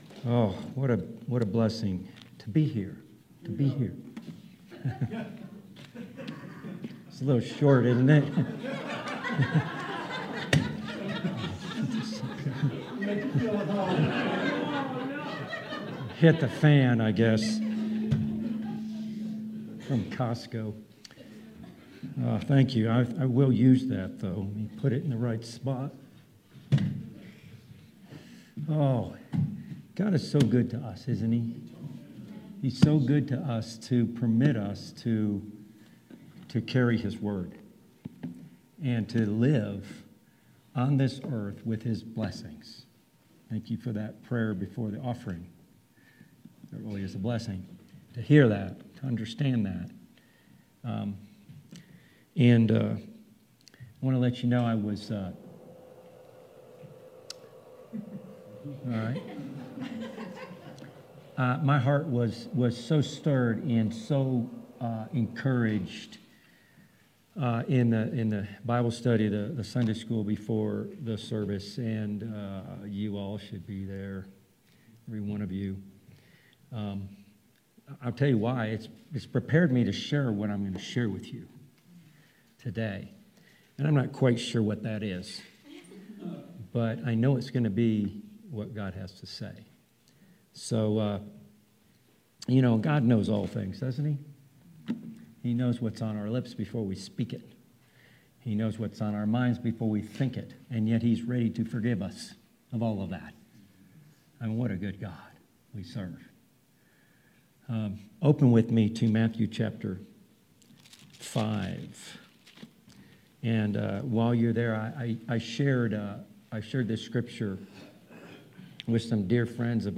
February 20th, 2022 Sermon